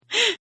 Woman Gasp 2